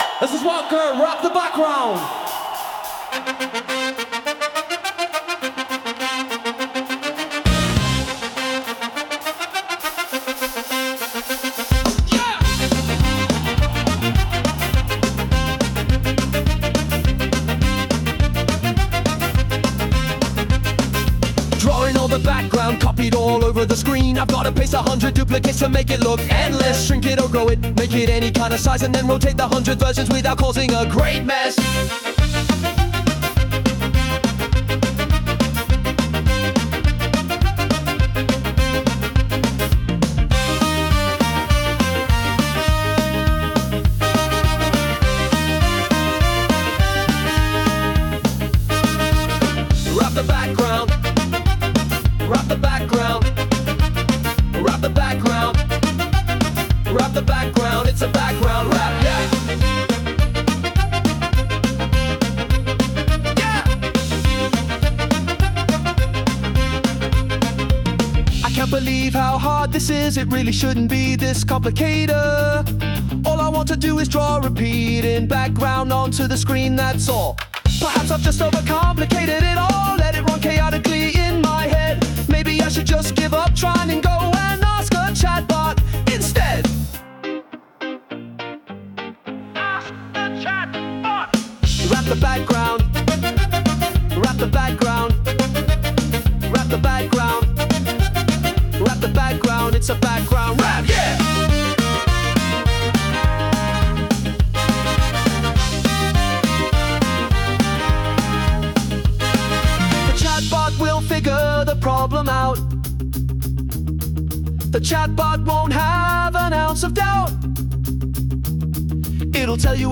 Ska version
Sung by Suno